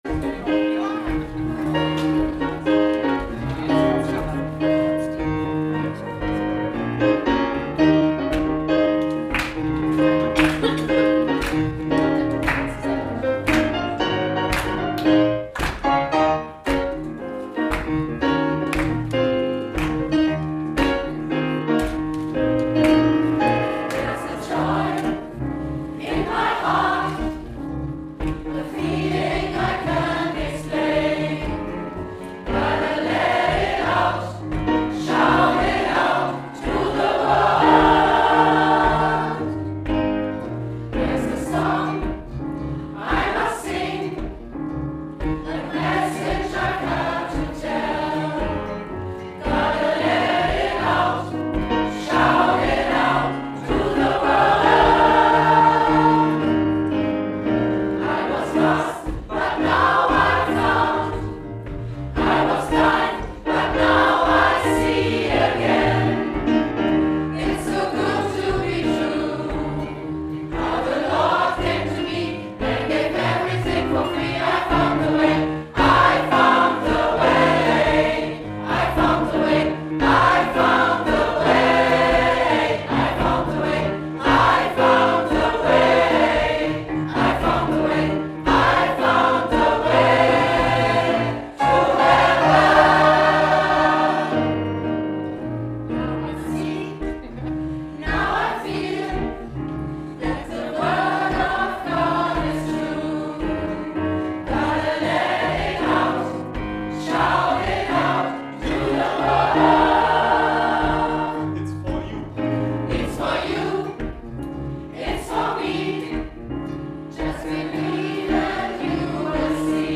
vierstimmig